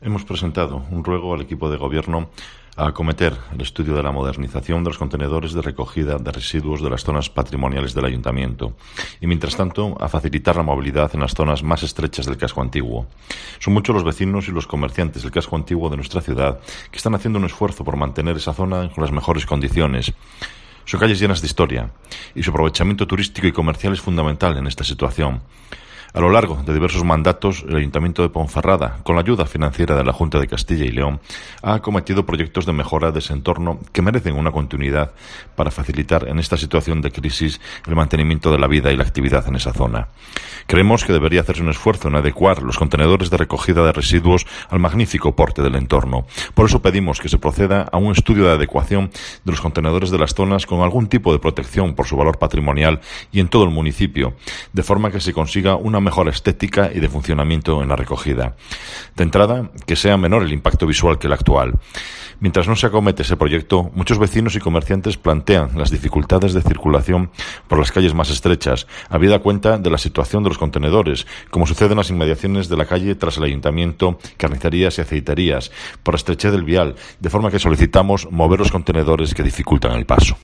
Escucha aquí al portavoz popular en la capital berciana, Marco Morala